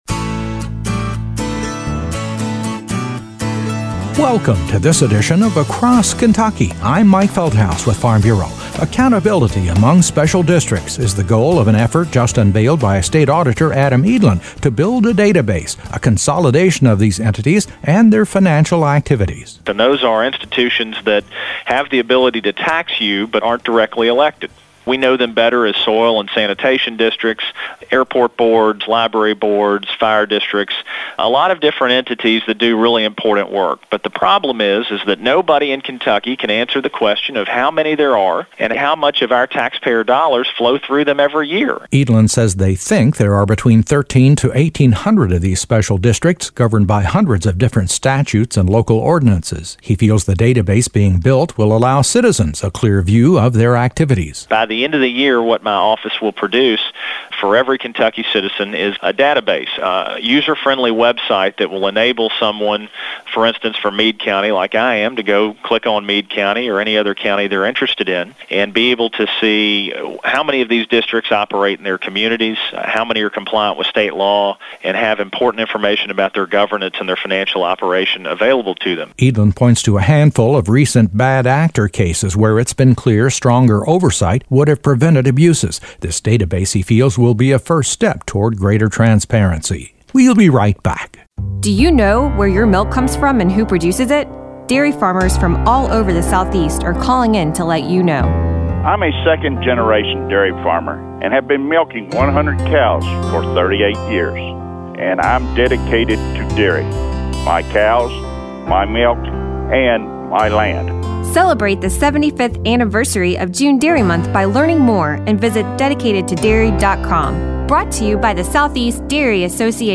State Auditor, Adam Edelen, comments.